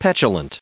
579_petulant.ogg